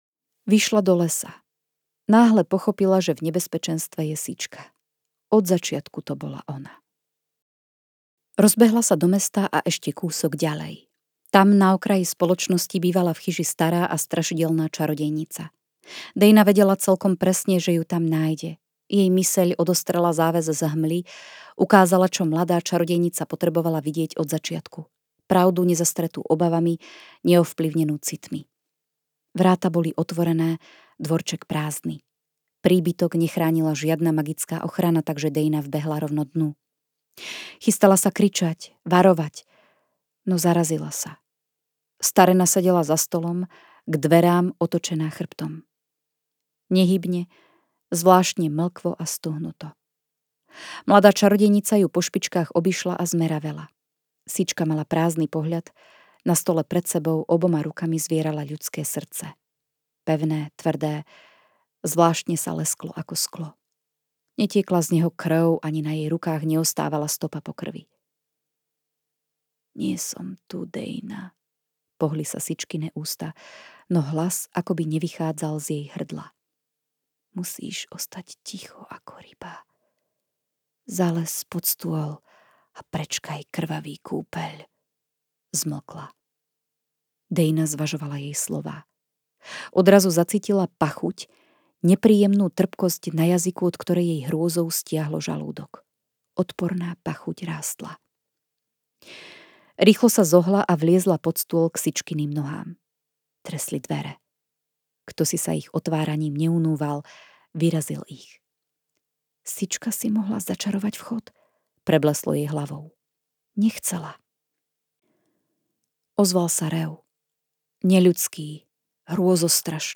Hlas príbehu prepožičala